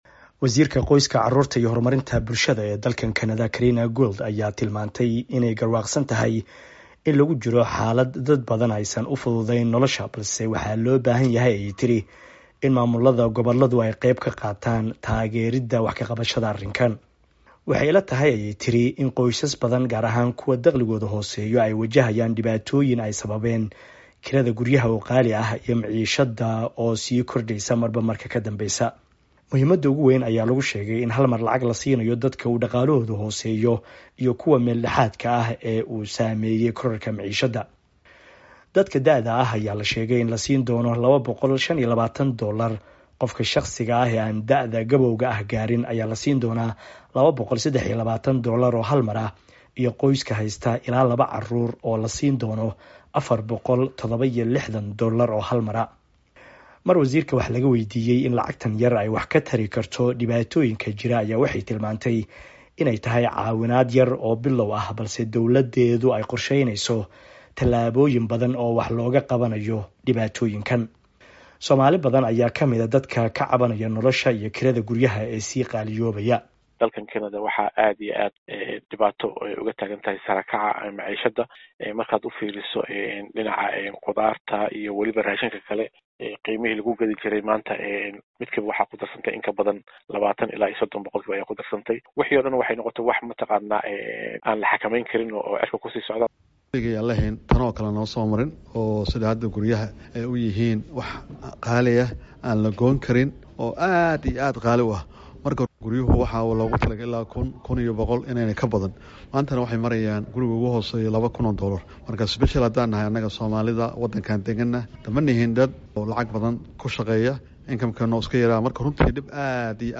Dedaallada ay dawladu ku doonayso inay shacabka noloshu ku adag tahay ku caawiso, waxaa ka mid ah in hal mar qof walba la siiyo lacag taageero ah, sida uu kusoo waramayo wariyaha VOA